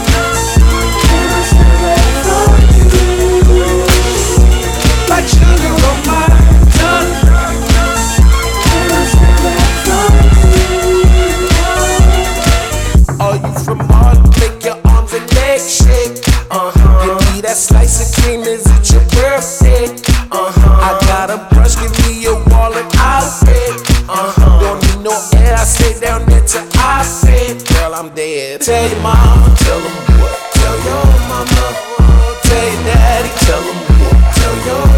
Rap Hip-Hop
Жанр: Хип-Хоп / Рэп